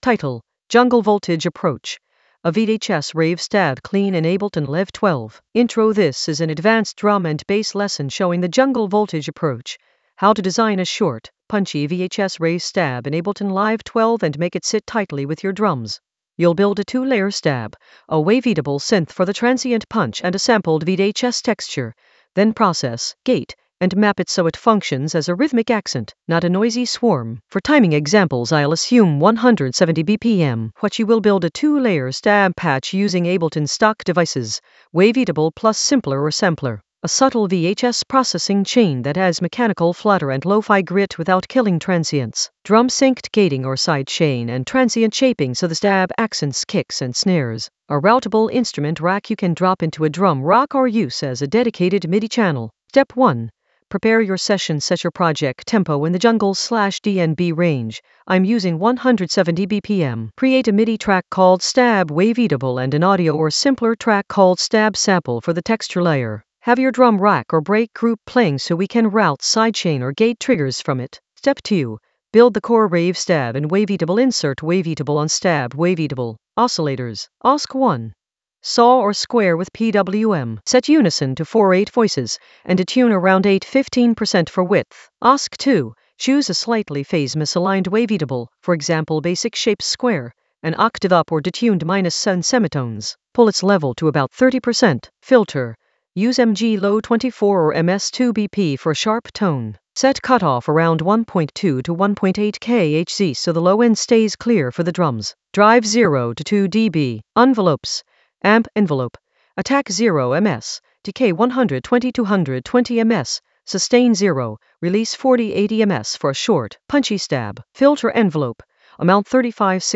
An AI-generated advanced Ableton lesson focused on Jungle Voltage approach: a VHS-rave stab clean in Ableton Live 12 in the Drums area of drum and bass production.
Narrated lesson audio
The voice track includes the tutorial plus extra teacher commentary.